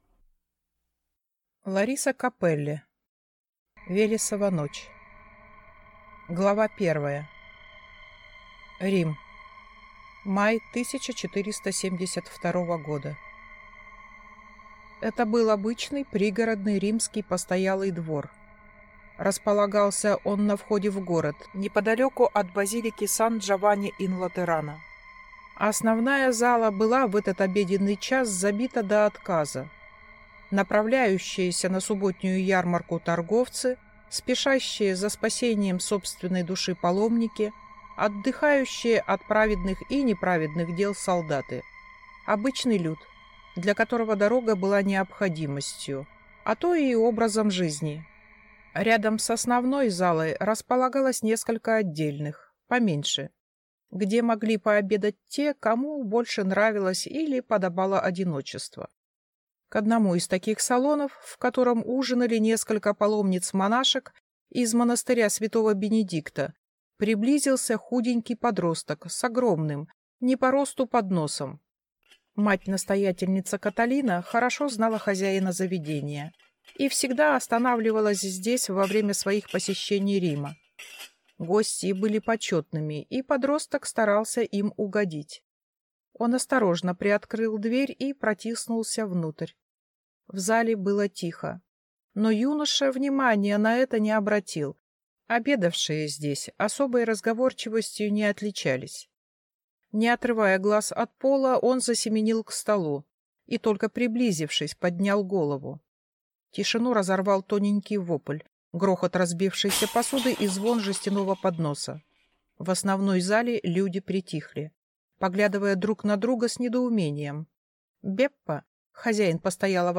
Аудиокнига Велесова ночь | Библиотека аудиокниг